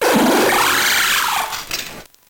Fichier:Cri 0601 NB.ogg